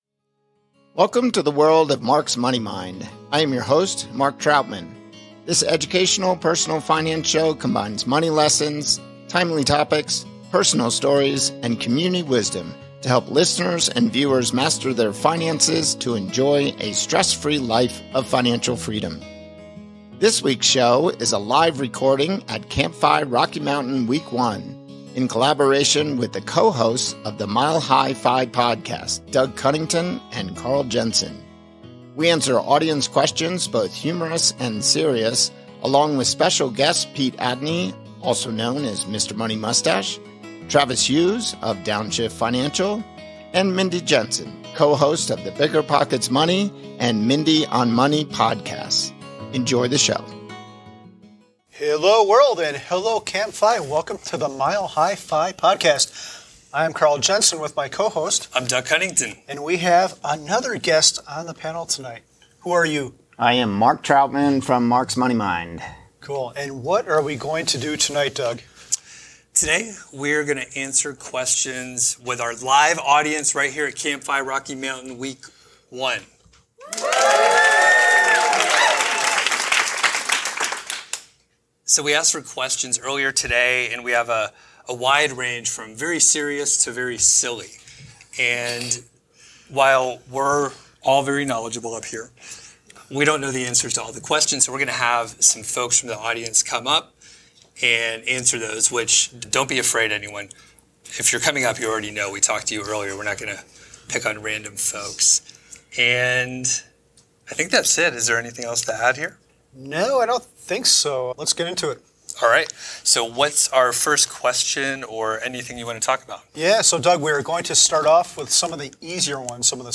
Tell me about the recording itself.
recorded live from CampFI Rocky Mountain Week 1